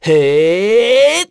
Esker-Vox_Casting1.wav